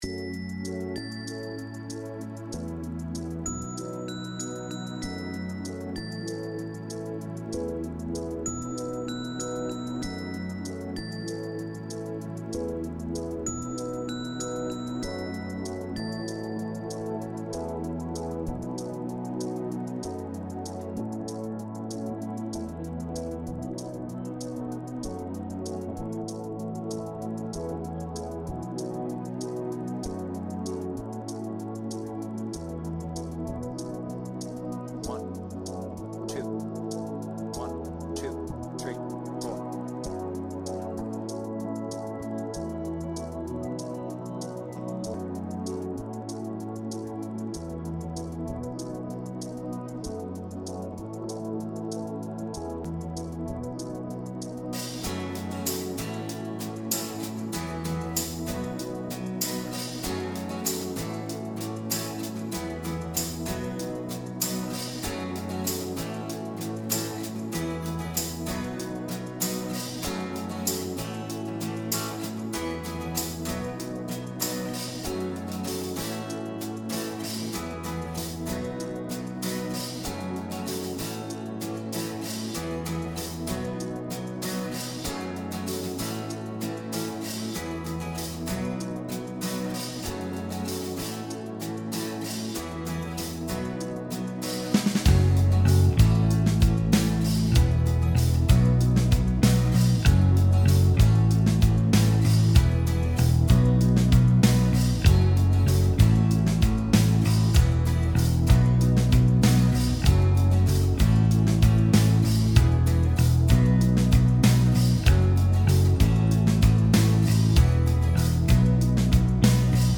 BPM : 146
With Vocals